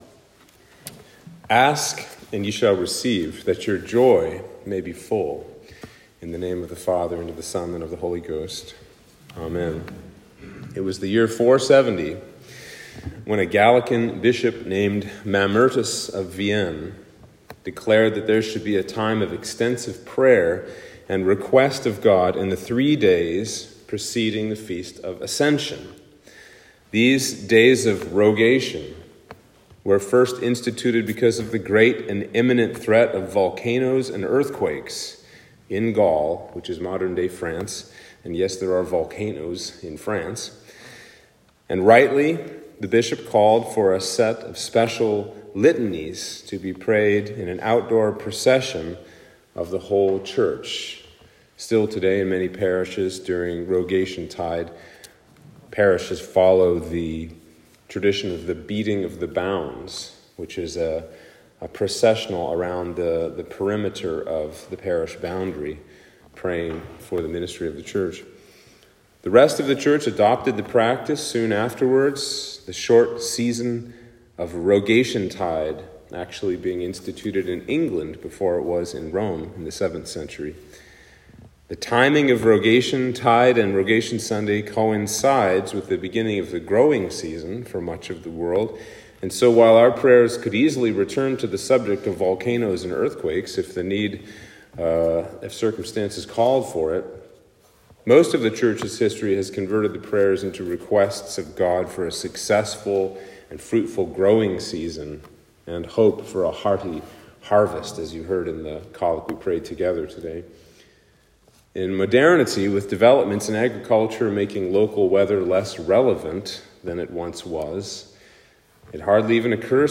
Sermon for Easter 5 - Rogation Sunday